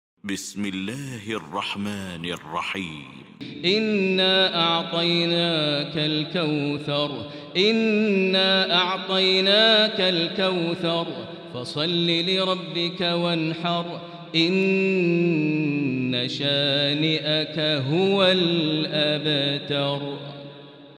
المكان: المسجد الحرام الشيخ: فضيلة الشيخ ماهر المعيقلي فضيلة الشيخ ماهر المعيقلي الكوثر